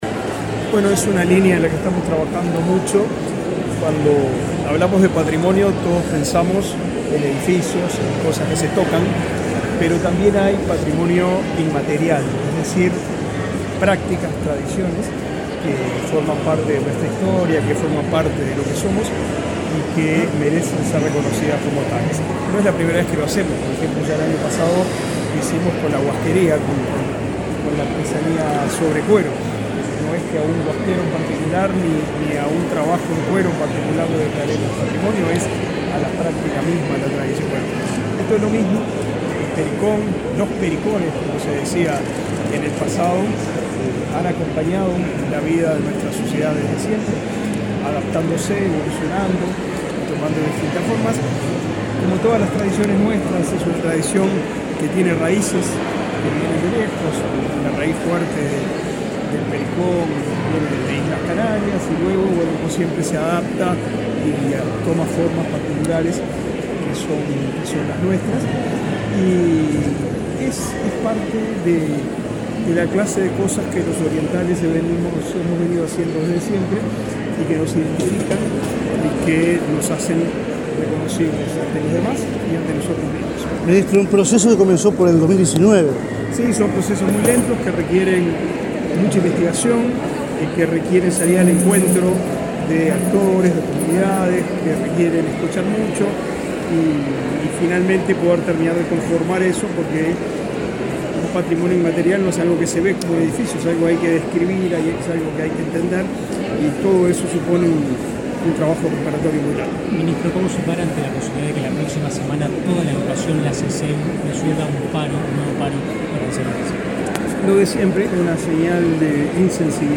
Declaraciones del ministro de Educación y Cultura, Pablo da Silveira
El ministro de Educación y Cultura, Pablo da Silveira, dialogó con la prensa antes de participar en el acto de declaratoria del pericón nacional como